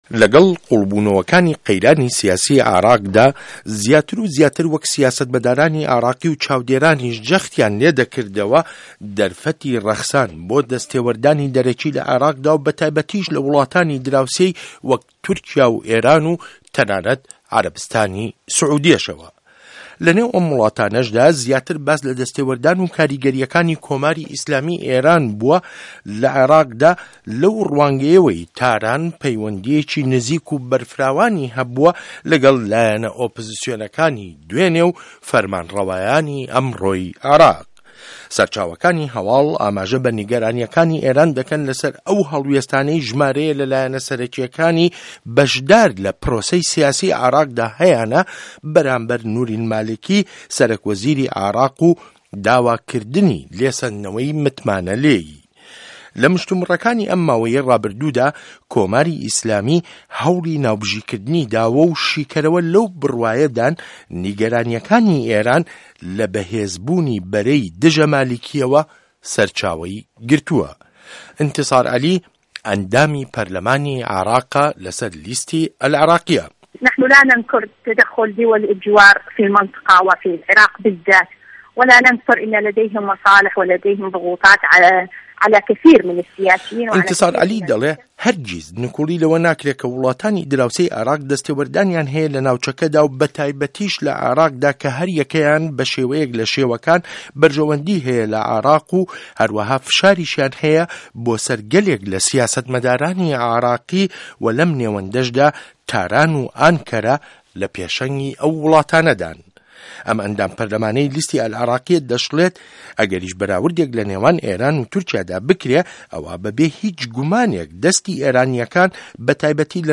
ڕاپۆرت له‌سه‌ر ڕۆڵی ئێران له‌ قه‌یرانی عێراقدا